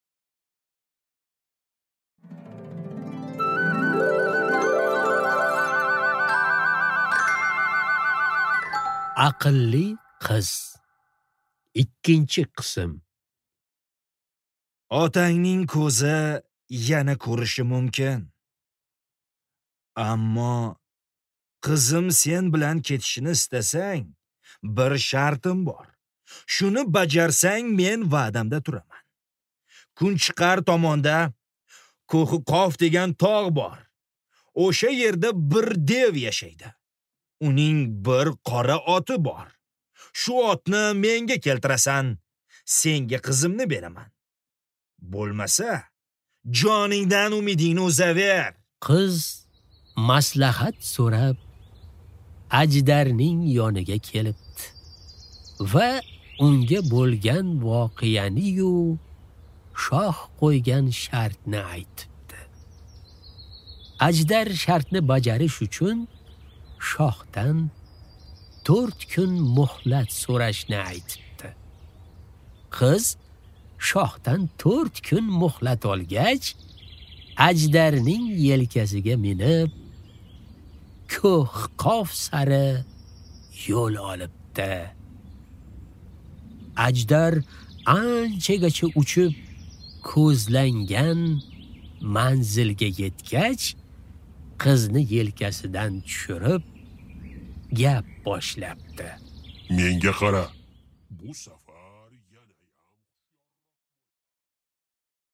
Аудиокнига Aqlli qiz. 2-qism | Библиотека аудиокниг